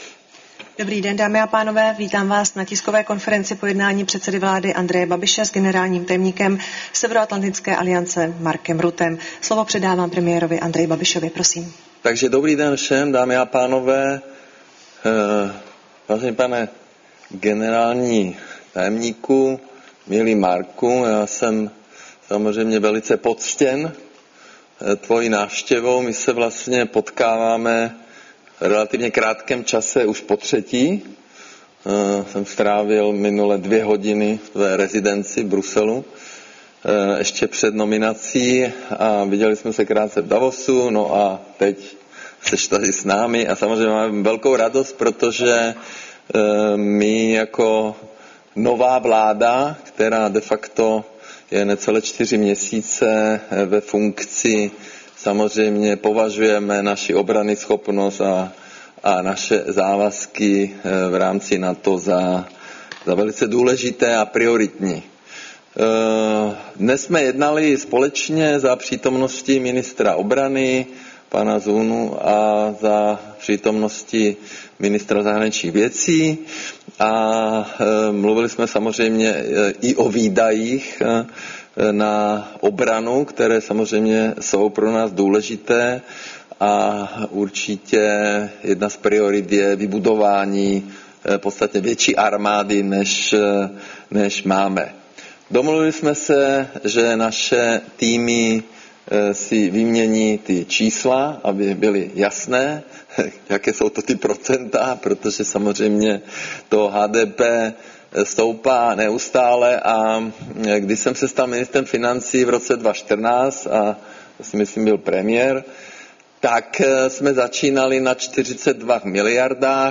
Tisková konference po setkání premiéra A. Babiše s generálním tajemníkem NATO Markem Ruttem, 16. dubna 2026